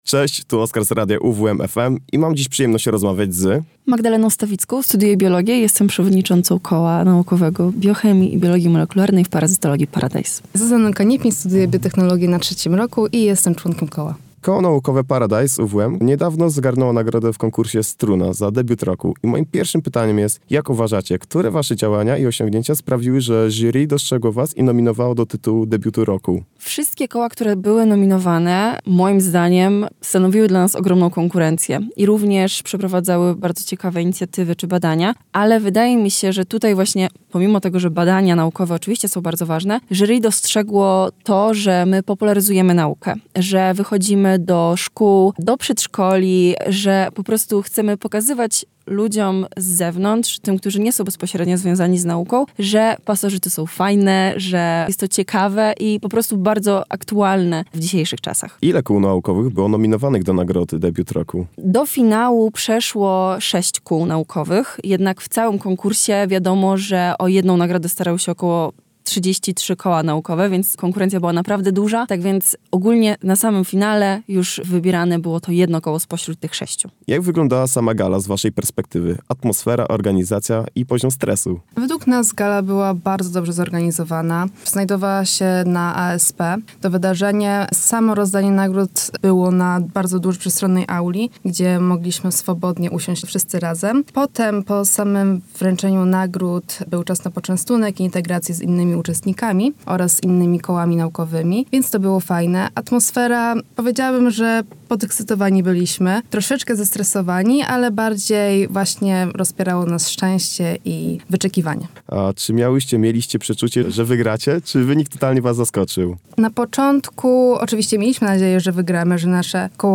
– mówiły w naszym studiu